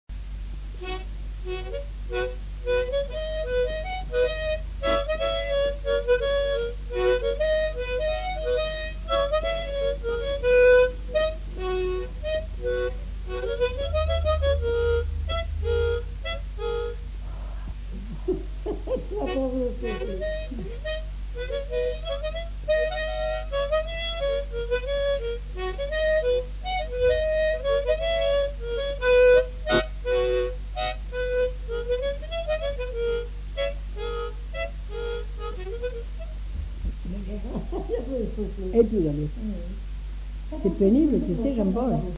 Mazurka
Aire culturelle : Haut-Agenais
Lieu : Cancon
Genre : morceau instrumental
Instrument de musique : harmonica
Danse : mazurka